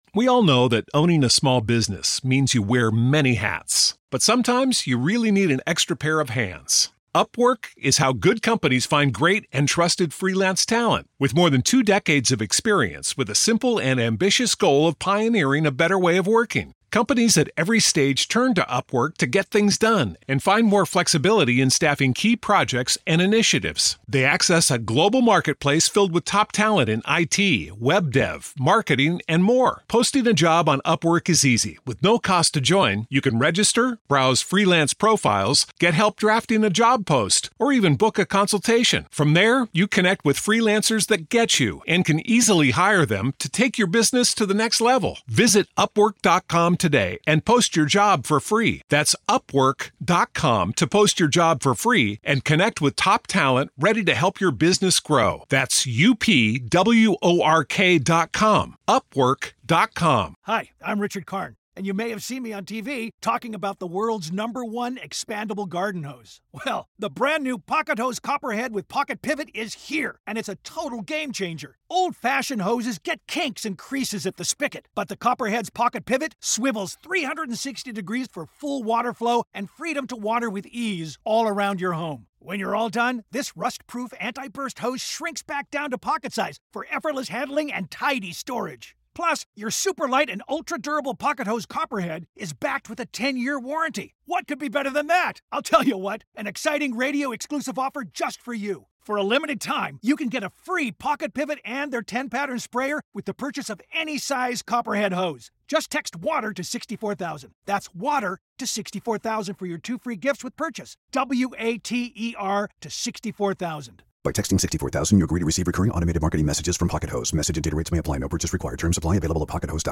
The lads are back with another rage filled deep dive into the past weeks top stories, and the very worst...